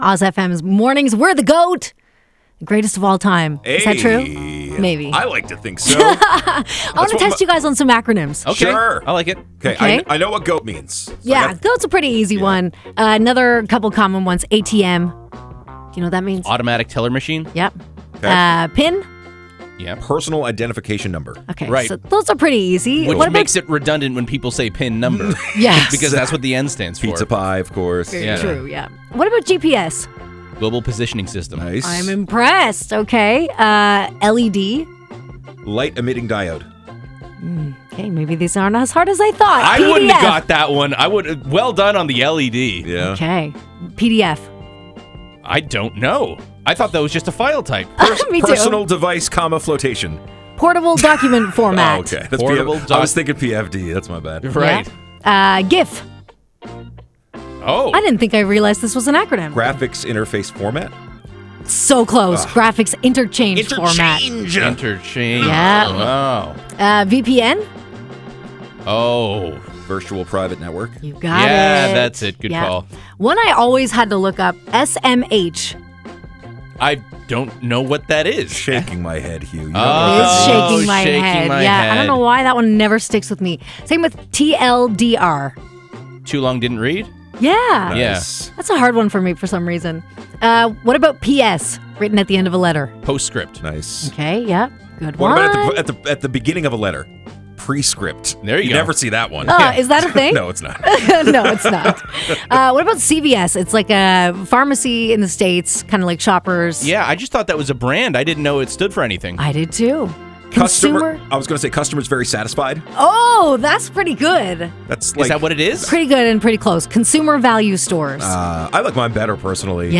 FYI: the OZ Mornings crew spent some time talking acronyms this morning.